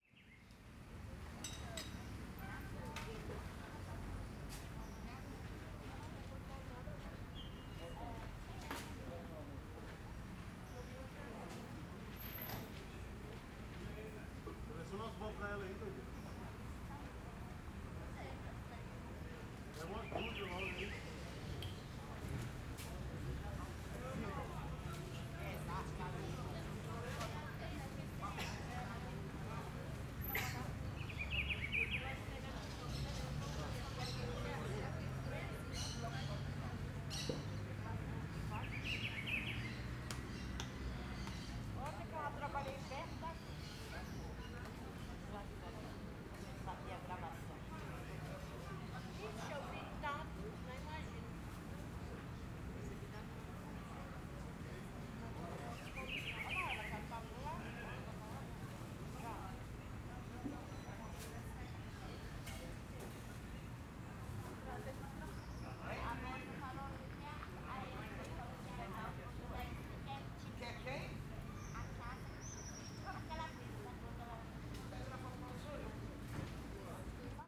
CSC-04-142-OL Ambiente Praca Vila Planalto manha passaros vozerio mulheres.wav